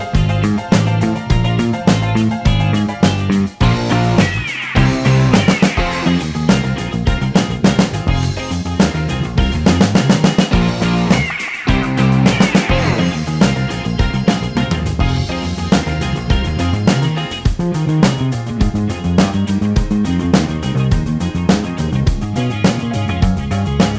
No Sax Solo Pop (1970s) 3:49 Buy £1.50